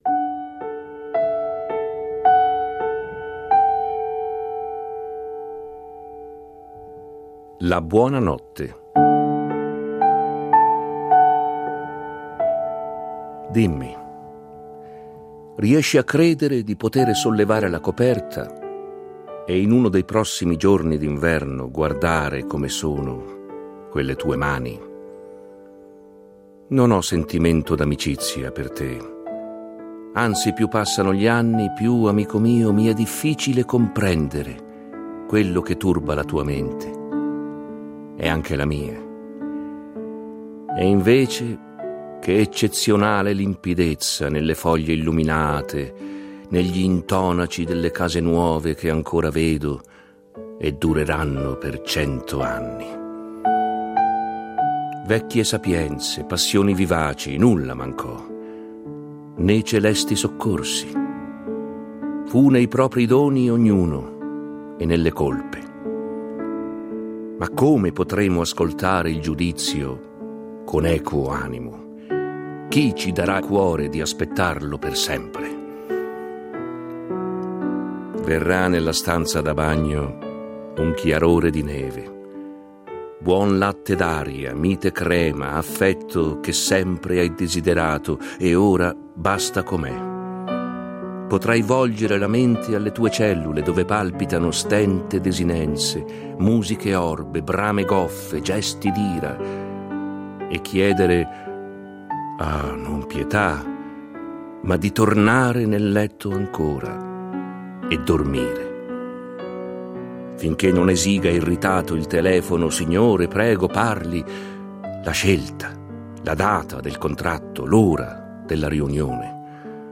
Musiche composte e interpretate al pianoforte